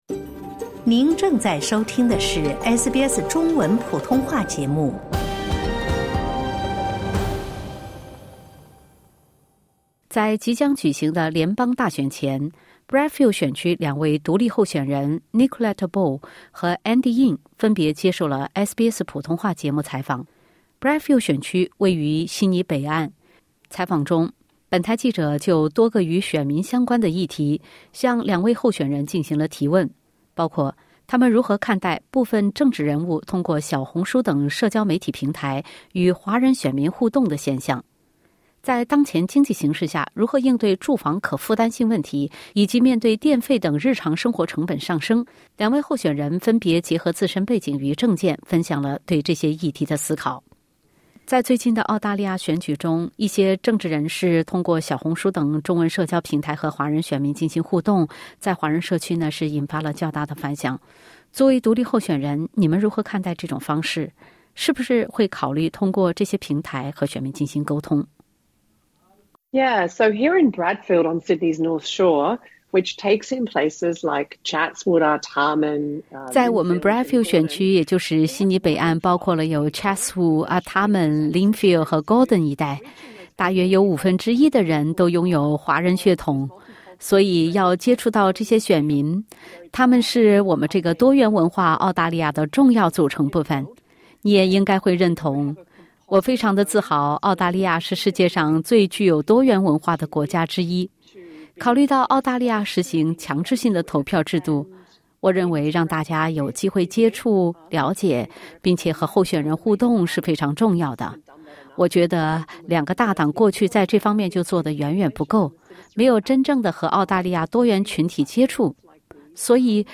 采访中，本台记者就多个与选民相关的议题，向两位候选人进行了提问。两位候选人分别分享了各自对这些议题的思考，并进一步阐述了他们对公共角色责任的理解。